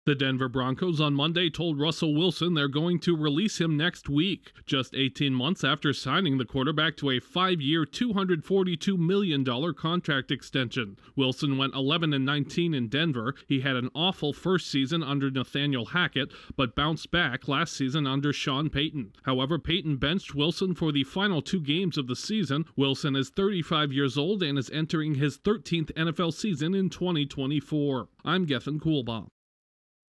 A nine-time Pro Bowl and Super Bowl-winning quarterback is set to be released. Correspondent